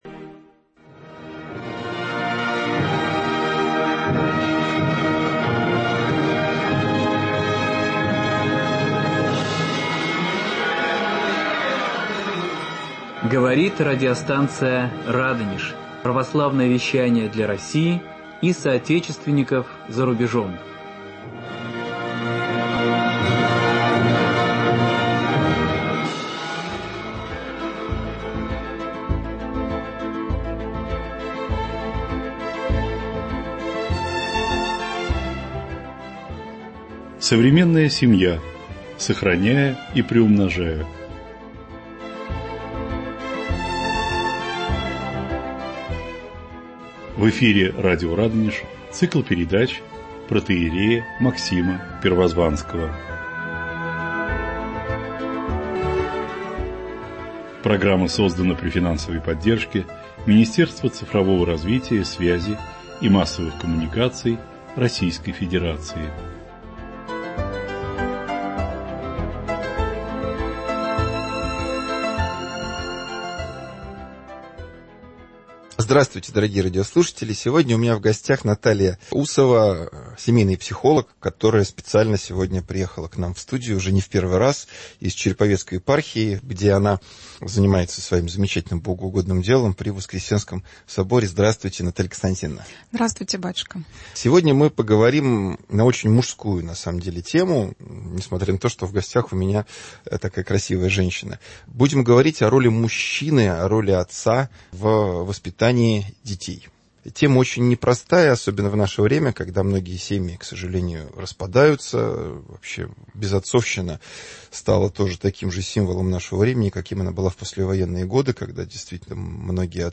Ведущий протоиерей
вместе с гостем, семейным психологом